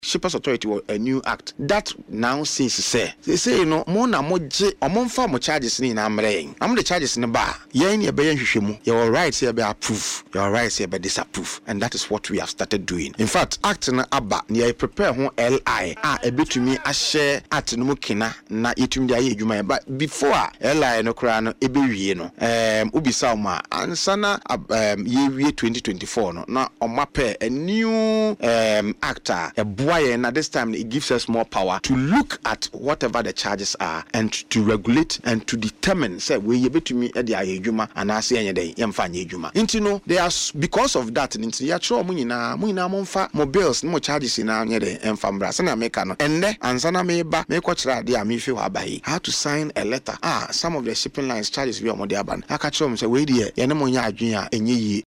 Speaking on Adom FM’s Burning Issues, Prof. Gyampo expressed concern over what he described as arbitrary increases in charges by some shipping lines, stressing the need for oversight to protect businesses and consumers.